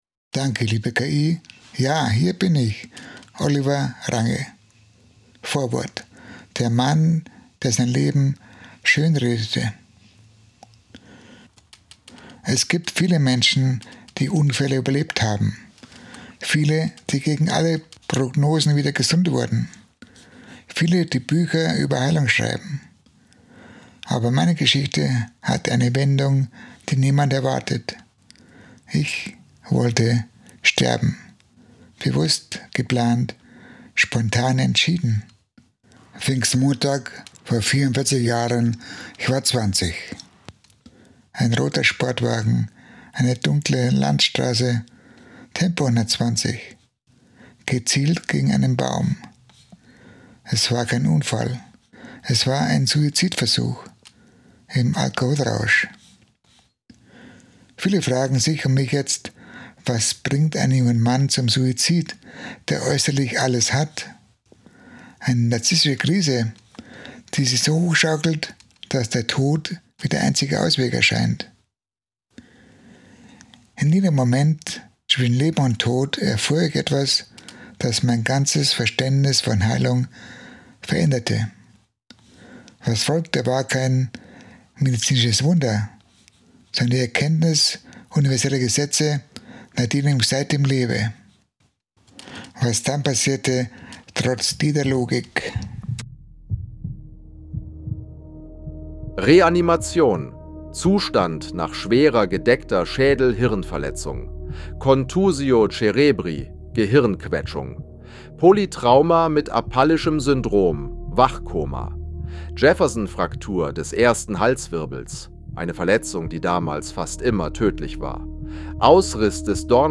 Hörbuch - Die 10 Gesetze der Heilung
Vorwort-Hoerprobe.mp3